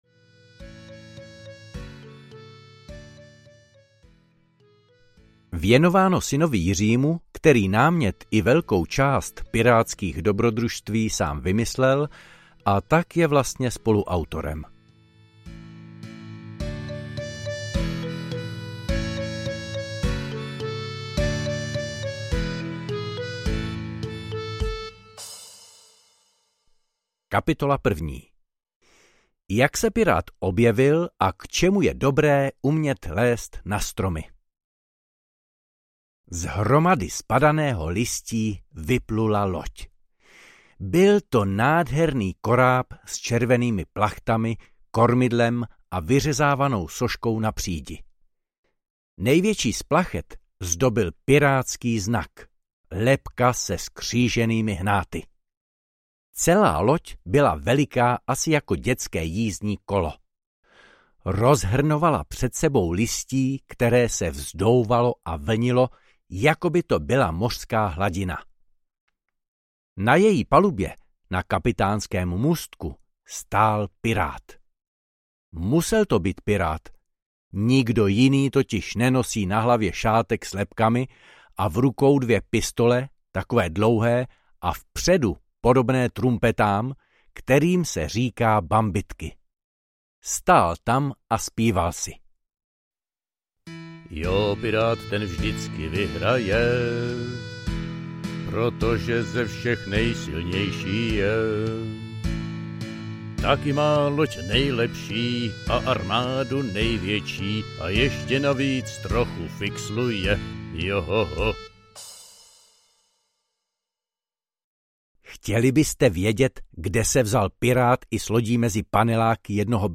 Pirát jménem Šampaňský audiokniha
Ukázka z knihy